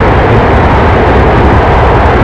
cfm-reverse.wav